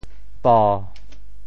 潮州发音 潮州 bo7